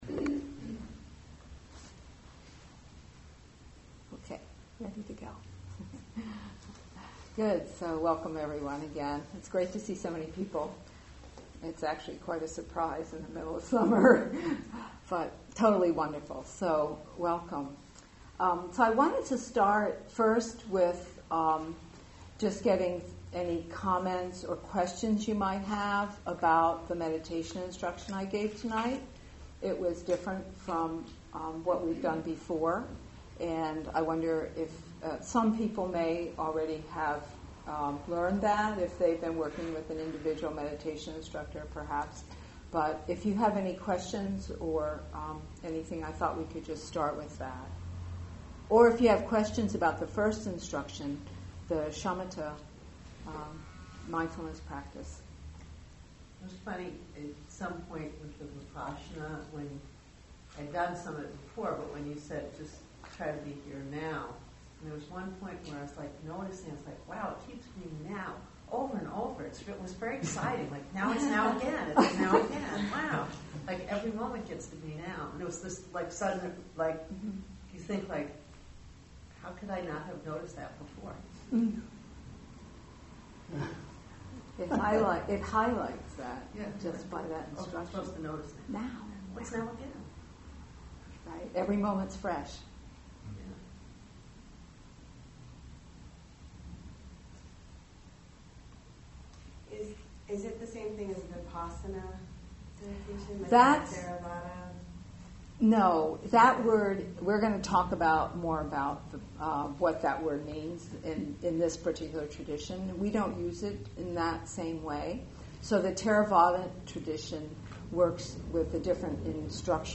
Class 1: Vipashyana as Expanded Awareness The meditation instructions given during the sitting period preceding the talk are an integral part of the class.